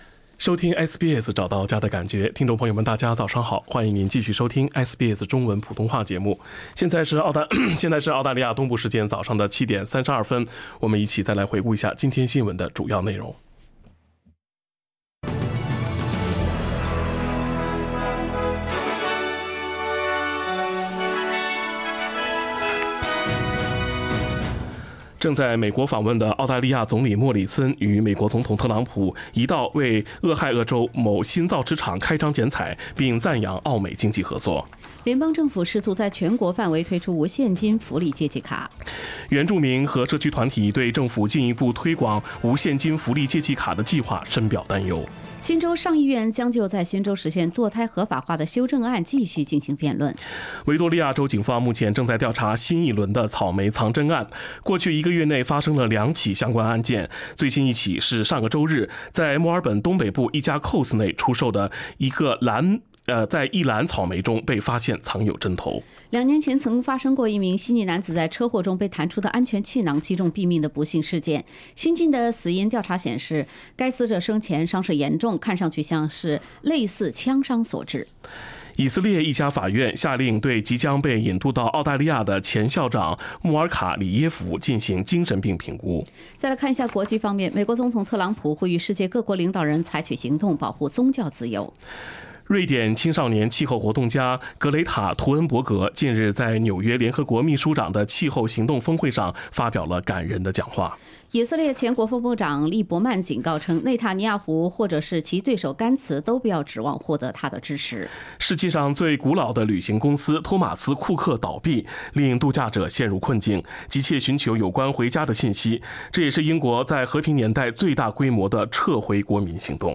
SBS早新闻（9月24日）